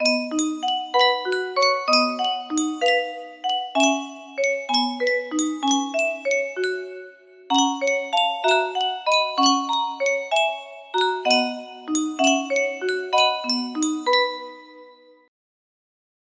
Yunsheng Music Box - Let Me Call You Sweetheart Y223 music box melody
Full range 60